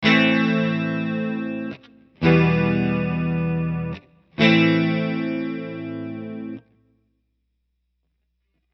Am To Em Back To Am 电吉他 110 Bpm
描述：电吉他上的几个和弦可以营造一种气氛。 如果你想要更多此类循环，请让我知道 谢谢你的聆听
Tag: 110 bpm Cinematic Loops Guitar Electric Loops 751.81 KB wav Key : Unknown